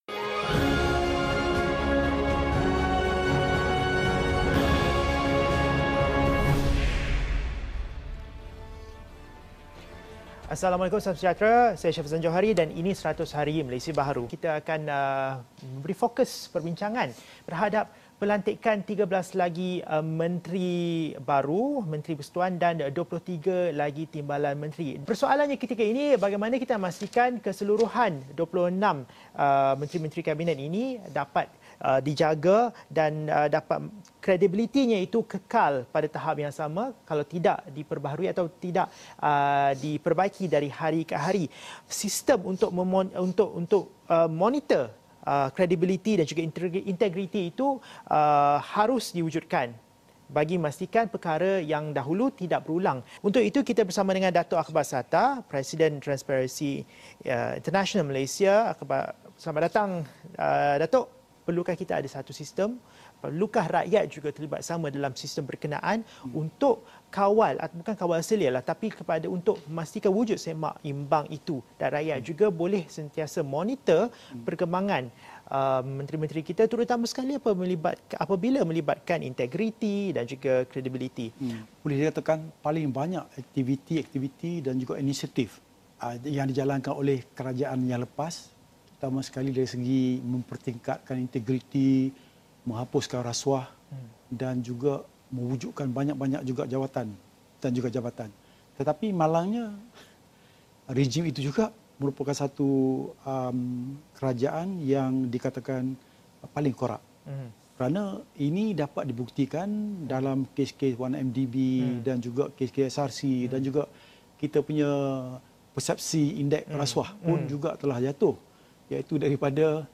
Dalam wawancara ini beliau turut menyentuh soal lantikan politik dalam pentadbiran syarikat berkaitan kerajaan (GLC).